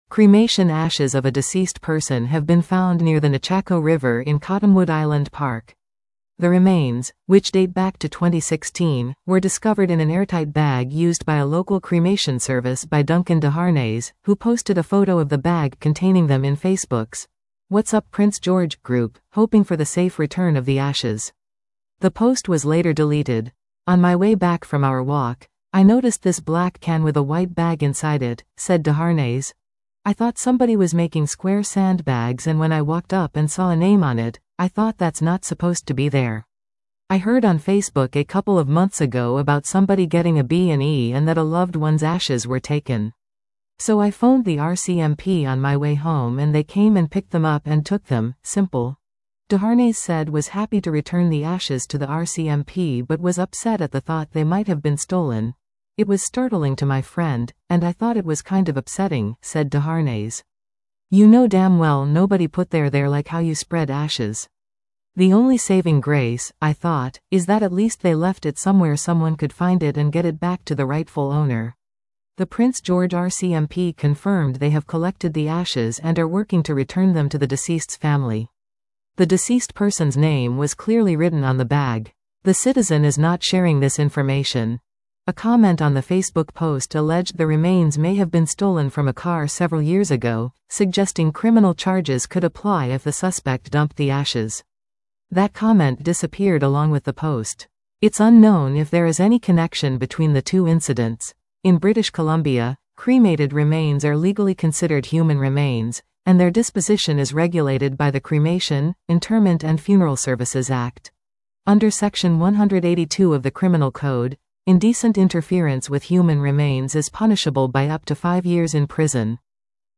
FACEBOOK Advertisement Expand Listen to this article 00:02:26 Cremation ashes of a deceased person have been found near the Nechako River in Cottonwood Island Park.